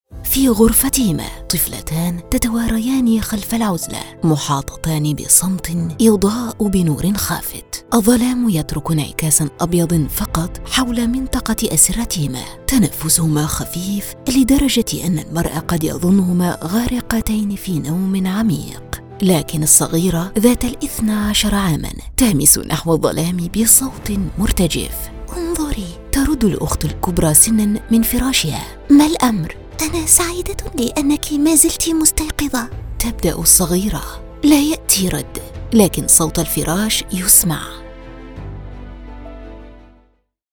Female
Friendly, Cheerful, Sad, Angry, Unfriendly, Whispering, Shouting, Terrified, Excited, Happy, energetic
Audiobook Acting
All our voice actors have professional broadcast quality recording studios.
1102Audio_book-Voice_acting_Sample.mp3